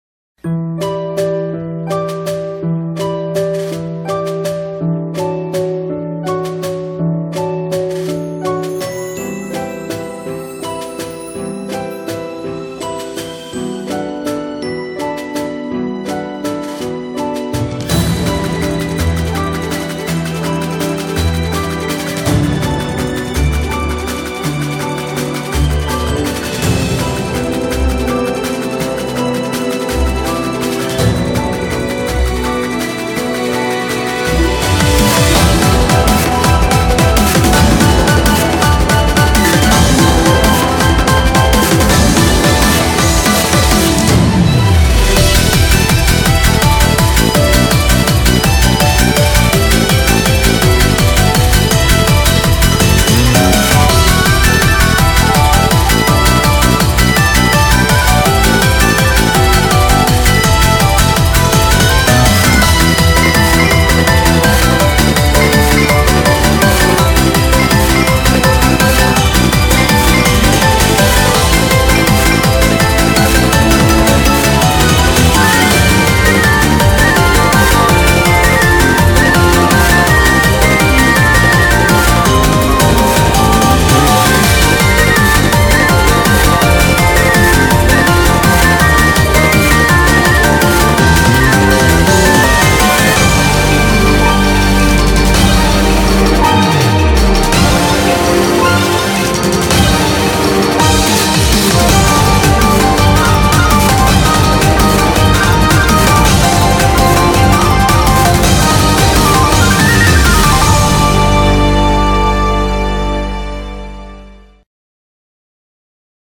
BPM83-165
Comments[SYMPHONIC]